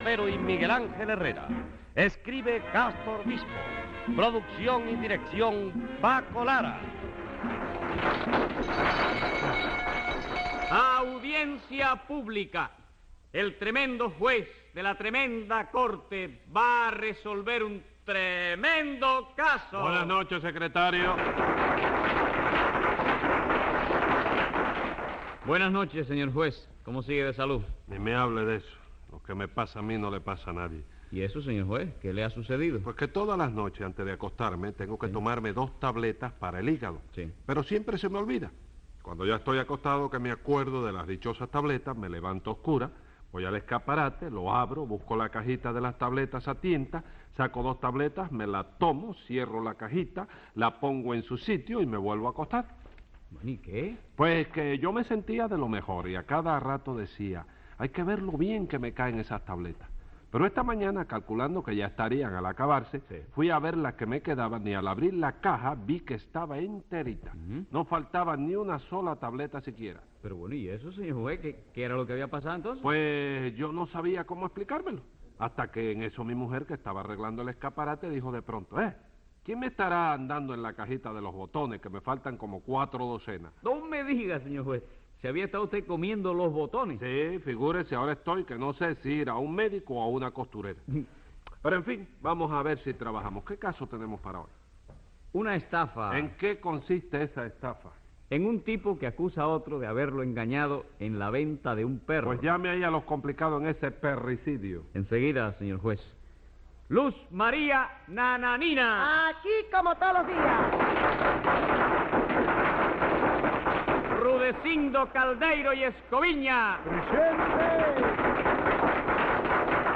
Divertidísimas situaciones en el más puro humor cubano en el legendario show de Tres Patines.